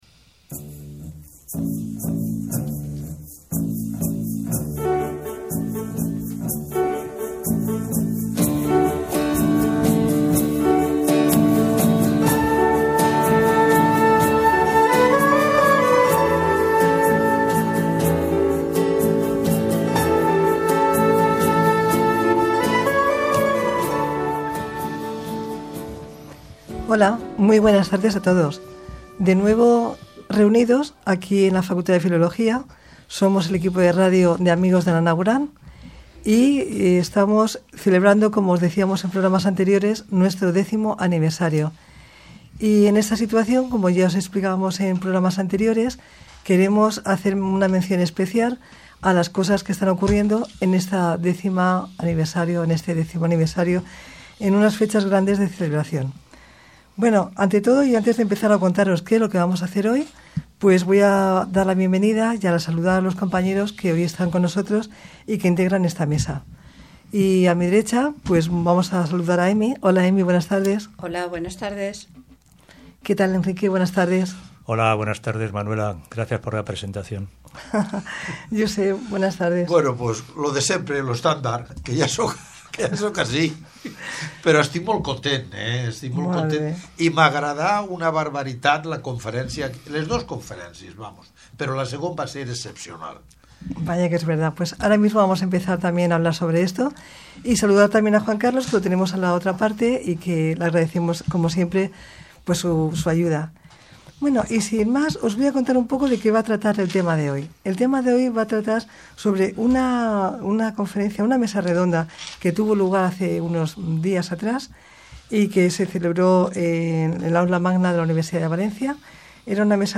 FRONTERAS ACTUALES DE LA CIENCIA-PROGRAMA DE RADIO